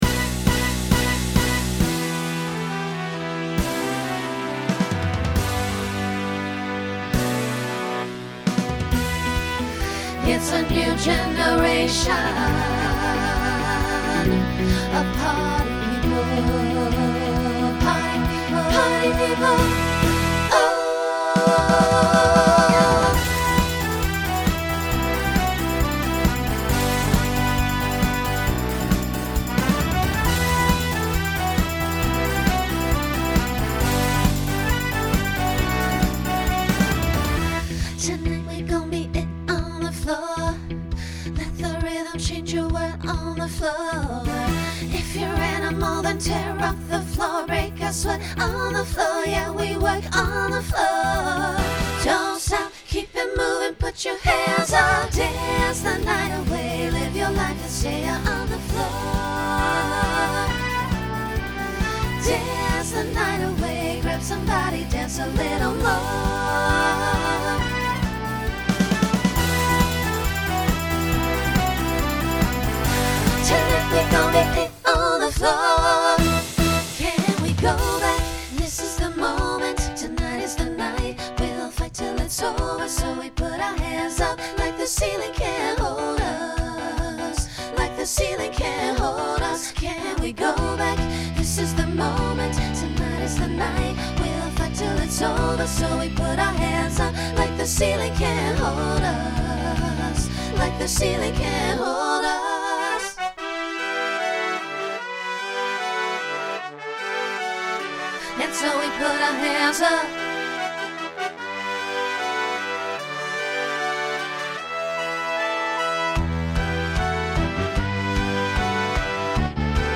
Genre Pop/Dance Instrumental combo
Voicing SSA